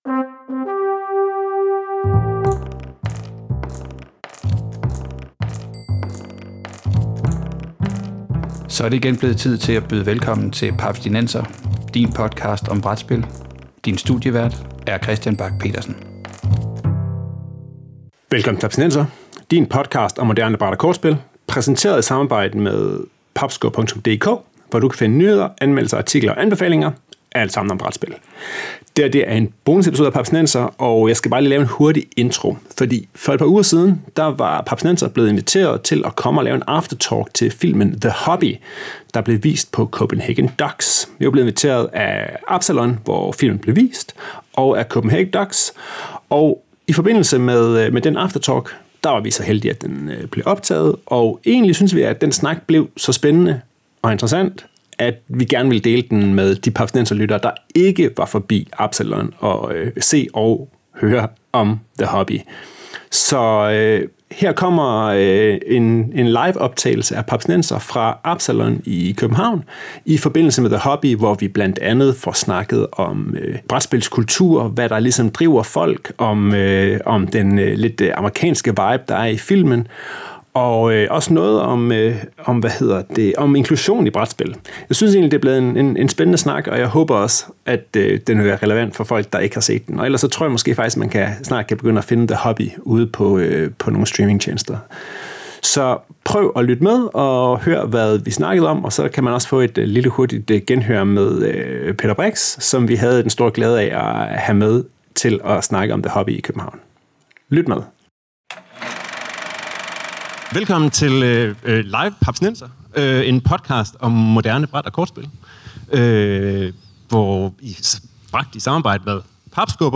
Livepodcast fra Absalon i København optaget som del af CPH:DOX.
Det blev til en halv times live-podcast, foran et veloplagt publikum, som også blev optaget og nu kommer som bonusepisode.
episode_the-hobby_live-at-absalon_2025.mp3